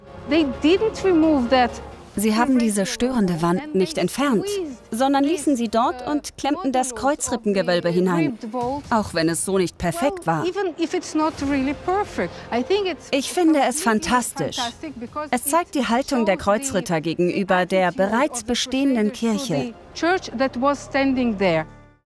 dunkel, sonor, souverän, sehr variabel
Mittel minus (25-45)
Doku, Off, Overlay